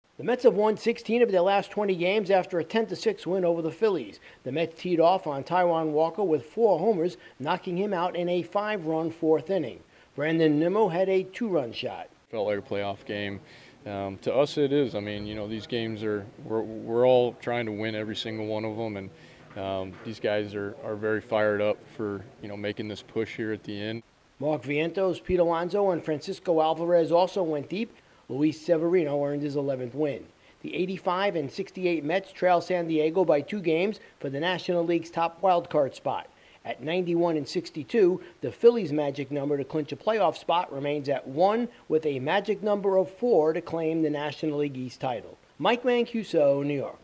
The Mets Mets delay the Phillies' playoff clinch with a 10-6 win. Correspondent